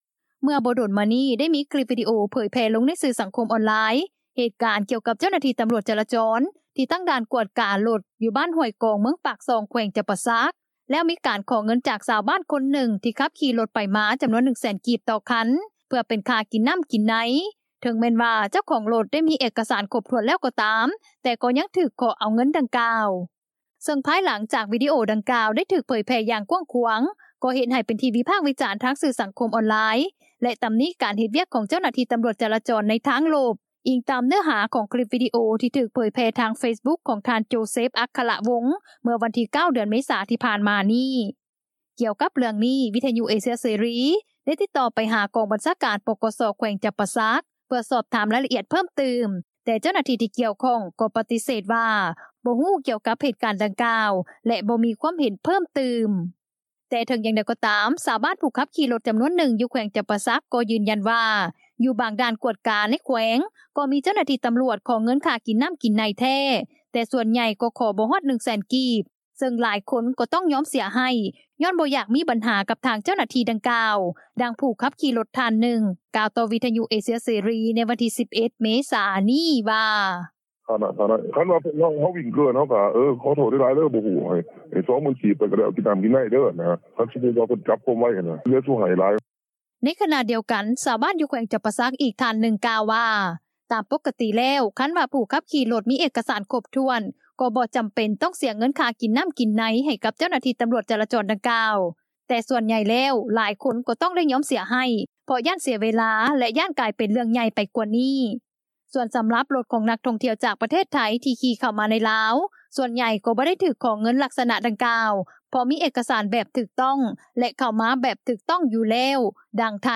ແຕ່ເຖິງຢ່າງໃດກໍ່ຕາມ ຊາວບ້ານ ຜູ້ຂັບຂີ່ລົດຈໍານວນໜຶ່ງ ຢູ່ແຂວງຈໍາປາສັກ ກໍ່ຢືນຢັນວ່າ ຢູ່ບາງດ່ານກວດກາໃນແຂວງ ກໍ່ມີເຈົ້າໜ້າທີ່ຕໍາຫຼວດ ຂໍເງິນຄ່າກິນນໍ້າກິນໃນແທ້ ແຕ່ສ່ວນໃຫຍ່ ກໍ່ຂໍບໍ່ຮອດ 100,000 ກີບ ເຊິ່ງຫຼາຍຄົນ ກໍ່ຕ້ອງຍອມເສຍໃຫ້ ຍ້ອນບໍ່ຢາກມີບັນຫາ ກັບທາງເຈົ້າໜ້າທີ່ດັ່ງກ່າວ, ດັ່ງຜູ້ຂັບຂີ່ລົດ ທ່ານໜຶ່ງ ກ່າວຕໍ່ວິທຍຸເອເຊັຽເສຣີ ໃນວັນທີ 11 ເມສາ ນີ້ວ່າ: